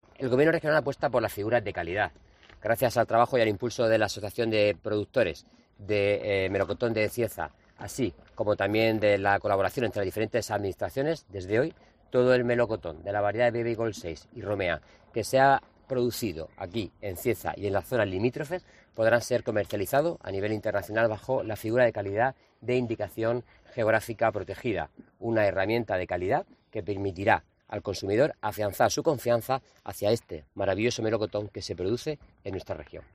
Antonio Luengo, consejero de Agua, Agricultura, Ganadería y Pesca